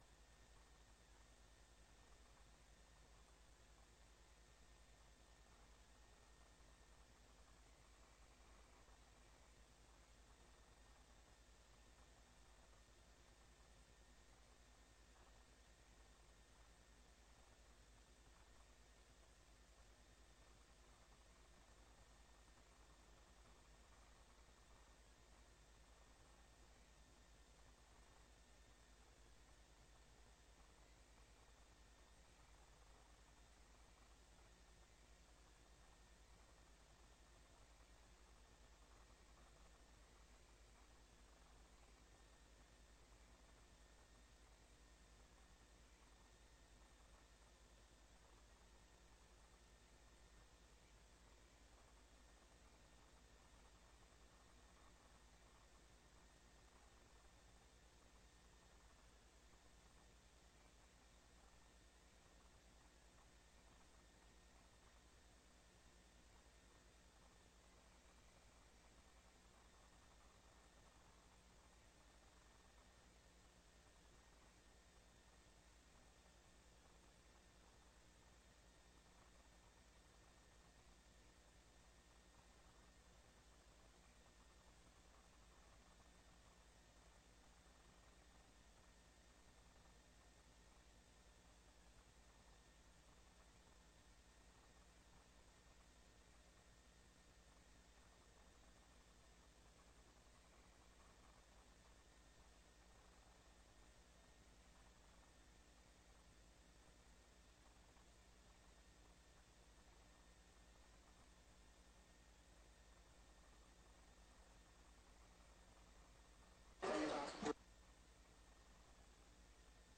Raadscommissie 09 oktober 2025 19:30:00, Gemeenteraad Hillegom
Locatie: Raadzaal